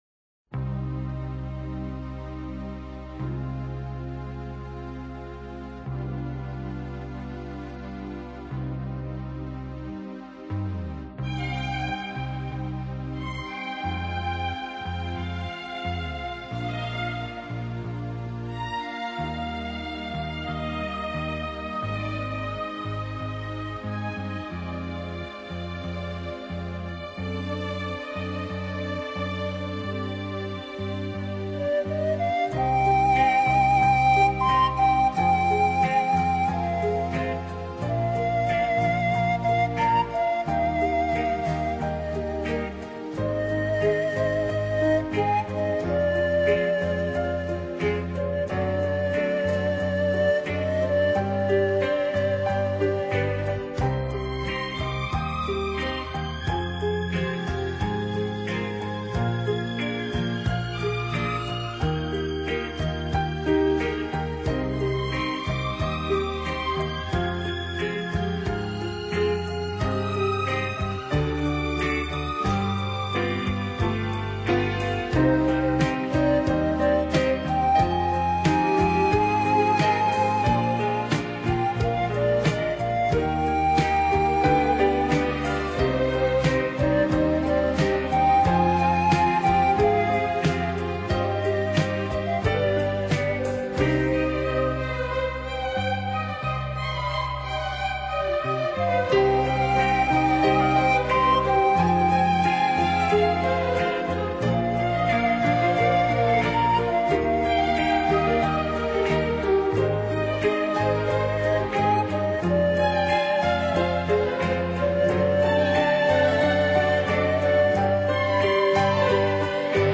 排笛音域宽广，音区变化大，音色丰富。